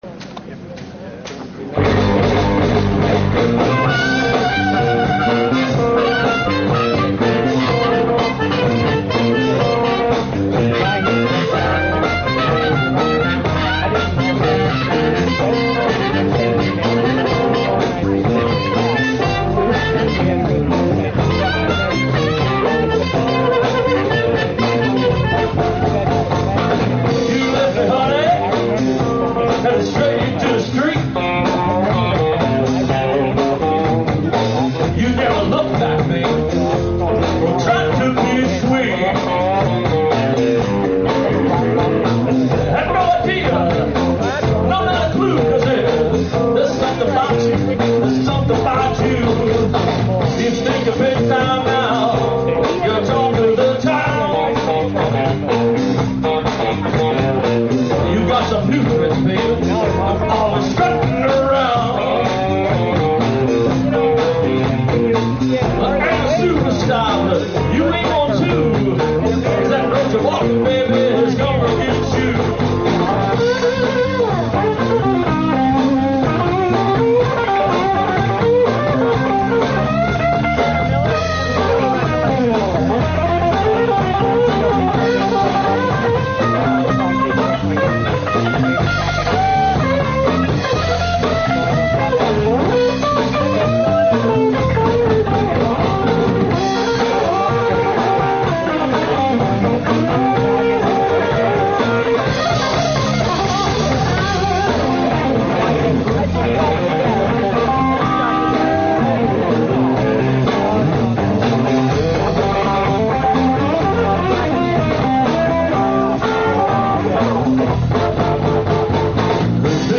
harmonica and lead vocals
guitar and vocals
bass and vocals
blues-rock